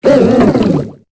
Cri de Moyade dans Pokémon Épée et Bouclier.